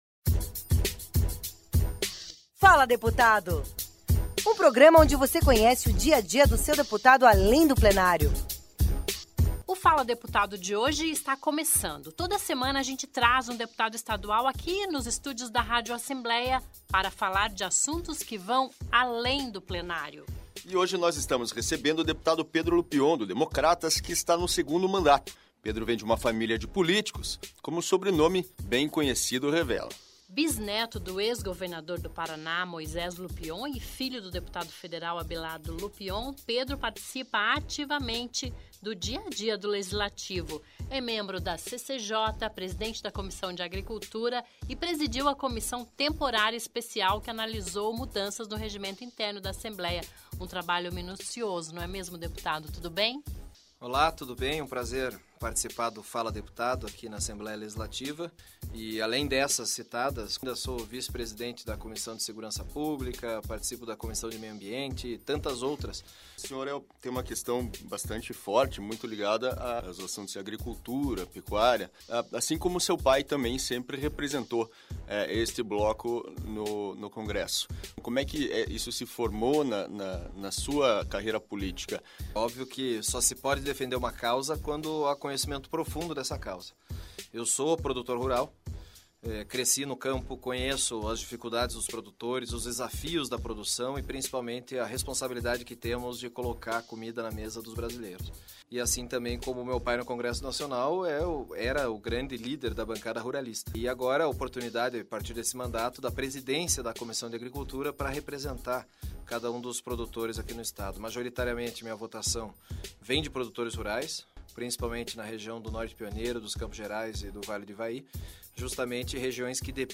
Ouça a entrevista com Pedro Lupion na íntegra aqui.